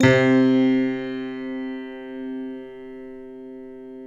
Index of /90_sSampleCDs/Optical Media International - Sonic Images Library/SI1_Six Pianos/SI1_Distantpiano